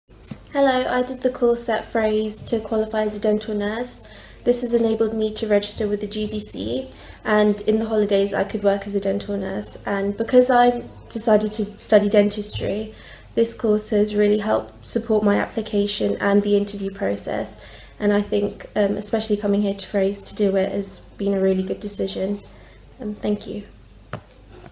Below, you’ll find a collection of testimonials from past participants.
Audio Testimonials